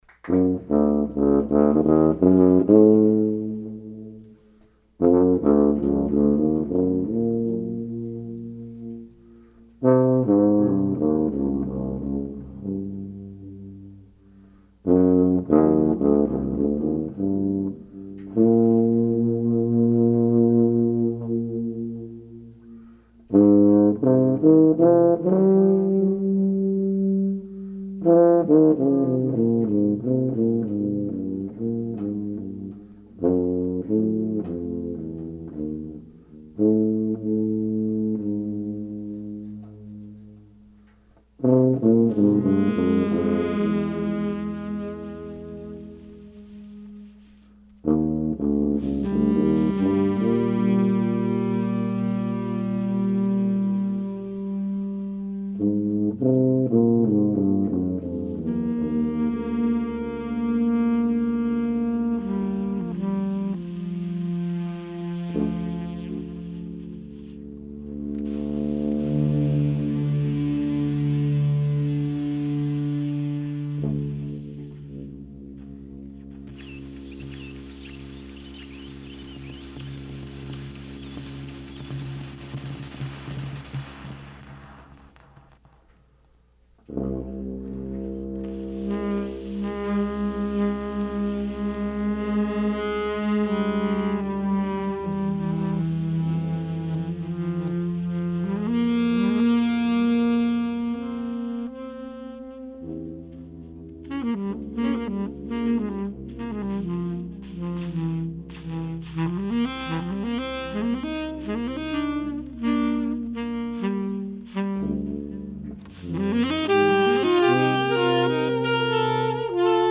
Recorded live at Downtown Music Gallery in Manhattan
tuba
alto saxophone, electronics
Stereo (722 / Pro Tools)